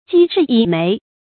赍志以没 jī zhì yǐ méi
赍志以没发音
成语注音ㄐㄧ ㄓㄧˋ ㄧˇ ㄇㄟˊ